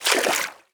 Footstep_Water_00.wav